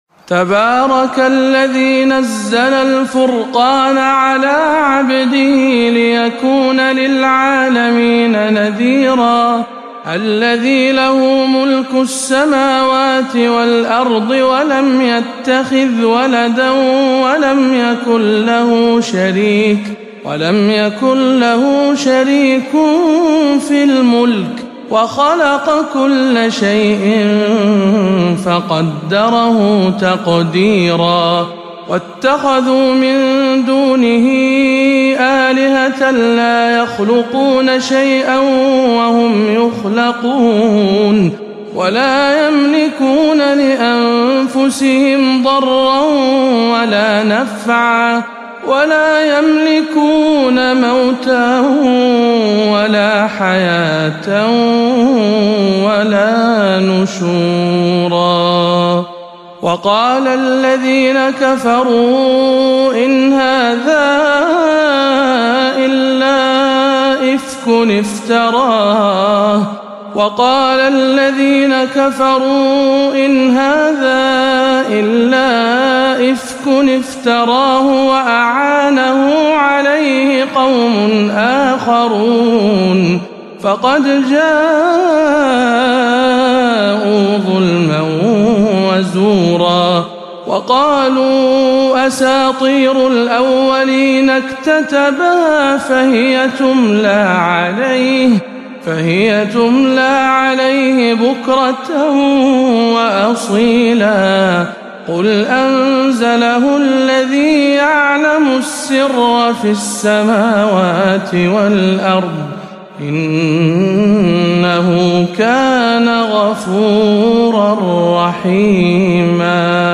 سورة الفرقان بمسجد معاوية بن أبي سفيان بالبحرين - رمضان 1438 هـ